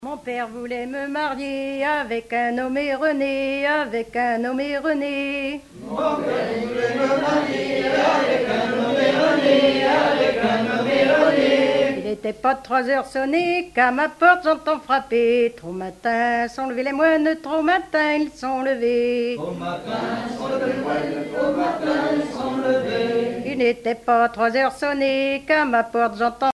Genre laisse
Chansons traditionnelles